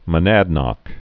(mə-nădnŏk), Mount